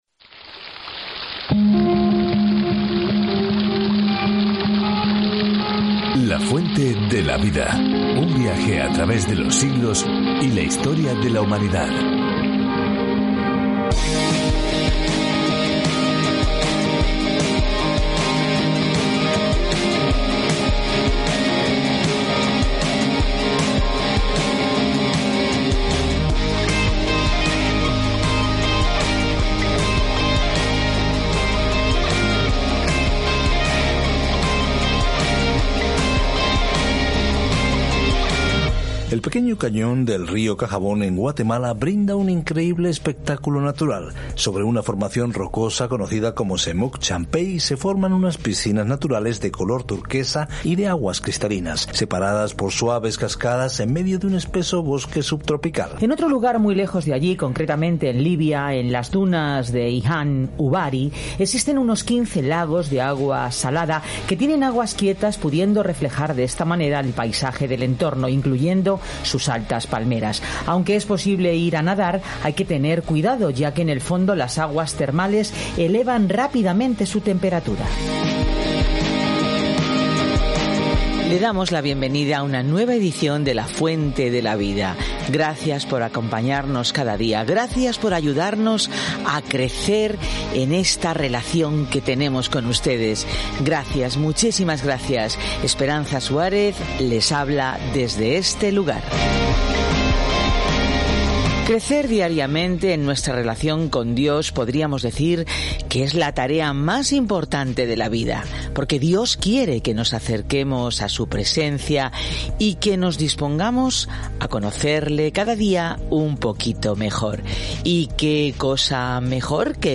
Escritura 1 REYES 18:29-46 1 REYES 19:1-4 Día 12 Iniciar plan Día 14 Acerca de este Plan El libro de Reyes continúa la historia de cómo el reino de Israel floreció bajo David y Salomón, pero finalmente se dispersó. Viaje diariamente a través de 1 Reyes mientras escucha el estudio de audio y lee versículos seleccionados de la palabra de Dios.